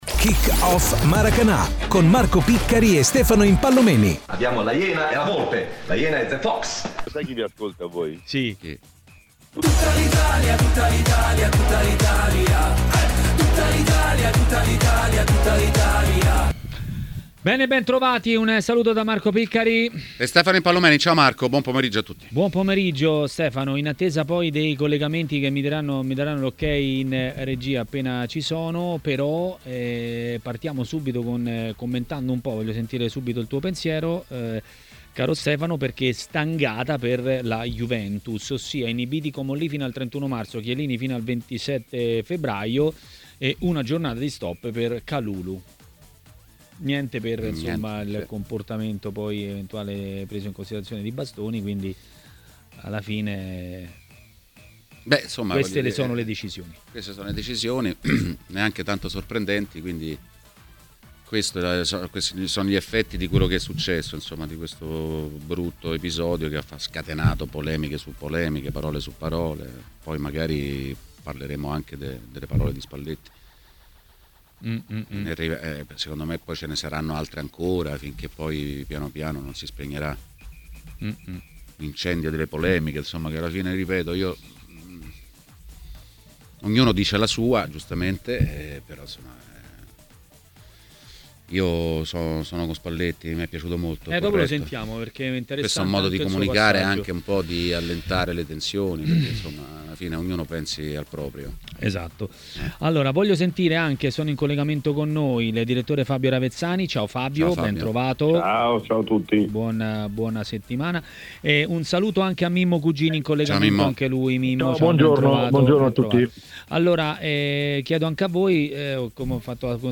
Ospite di Maracanà, nel pomeriggio di TMW Radio